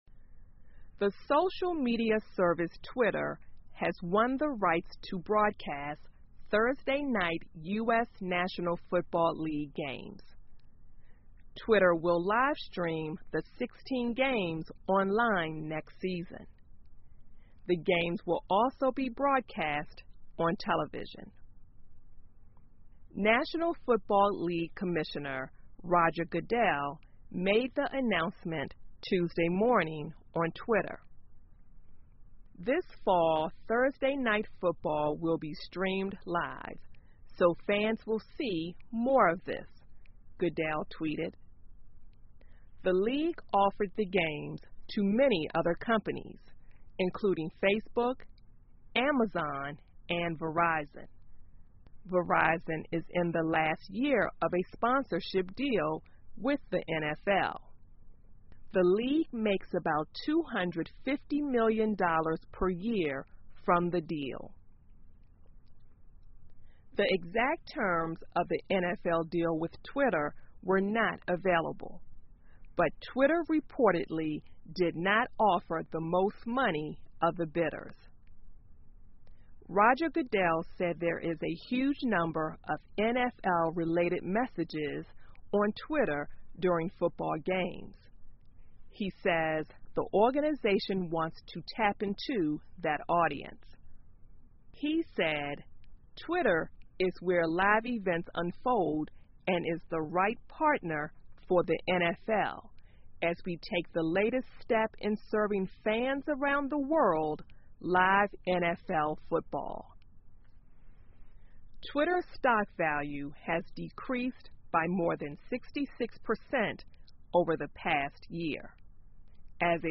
在线英语听力室推特播放美国足球比赛的听力文件下载,2016年慢速英语(四)月-在线英语听力室